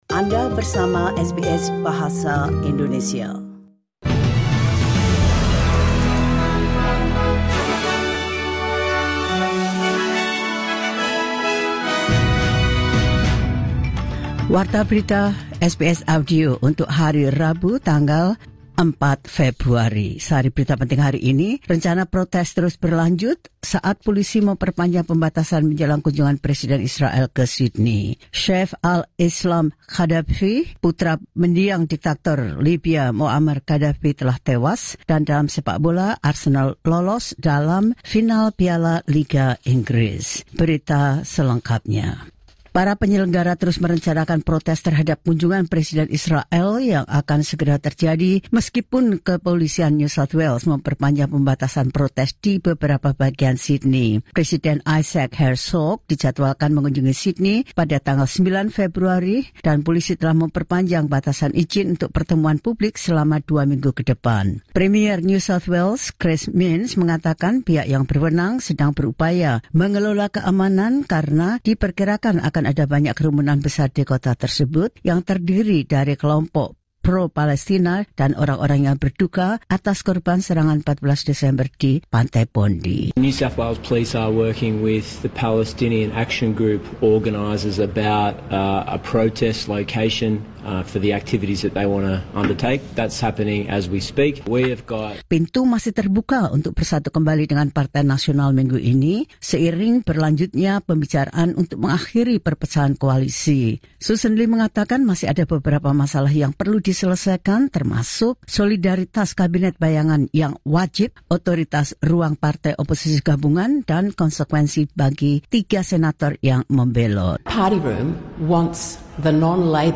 The latest news SBS Audio Indonesian Program – Wed 04 Feb 2026.